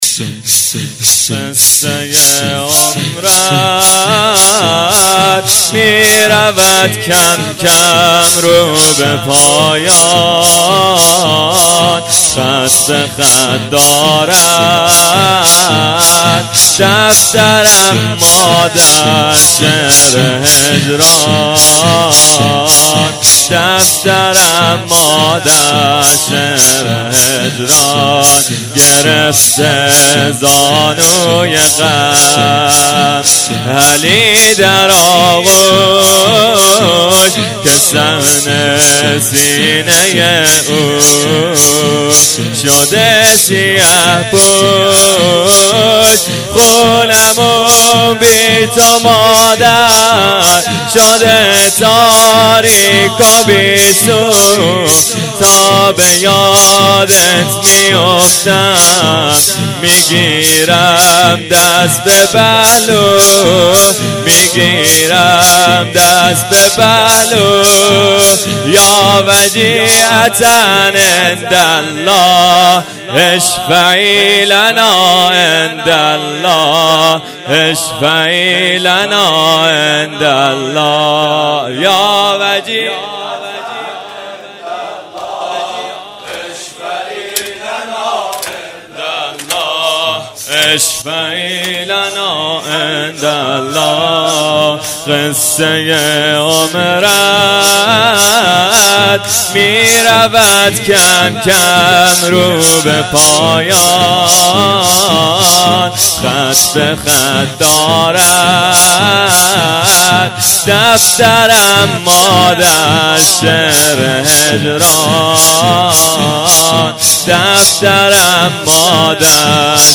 مراسم شب هفتم فاطمیه دوم 93/94(شب تحویل سال نو)